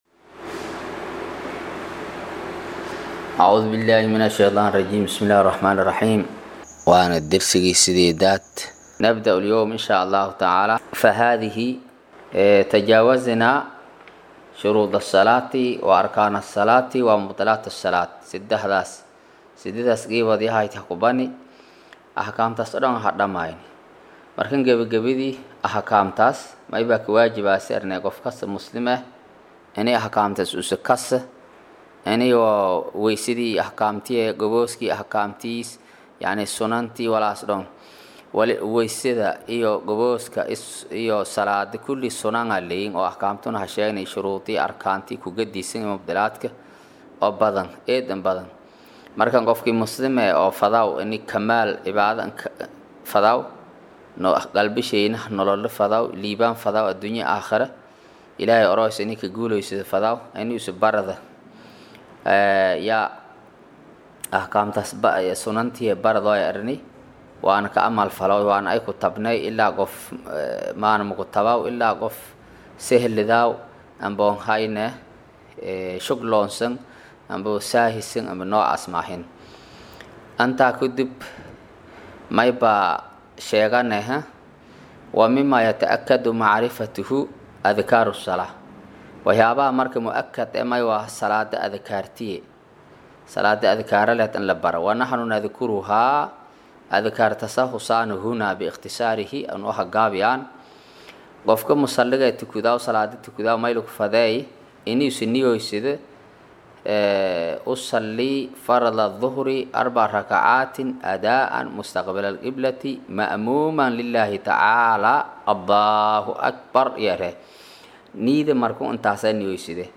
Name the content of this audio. Maqal: Casharka Safiinatu Salaad ” Darsiga 8aad